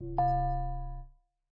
steeltonguedrum_c.ogg